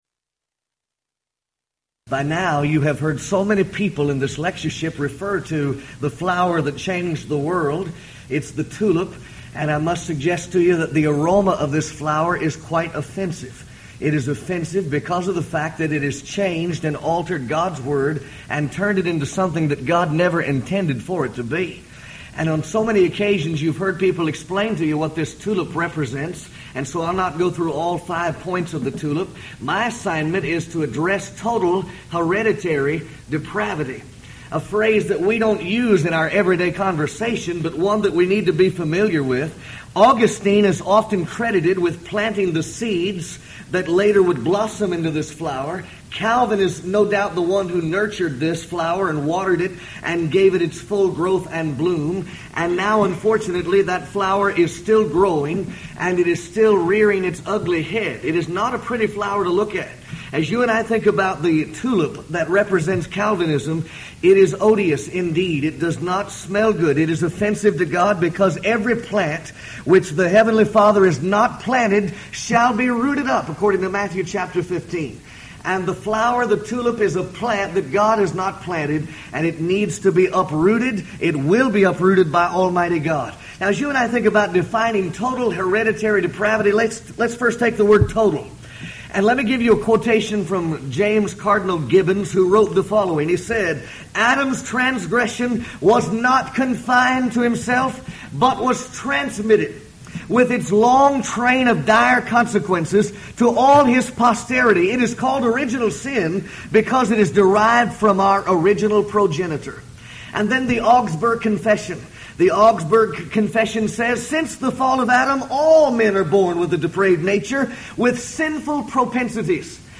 Event: 1998 Houston College of the Bible Lectures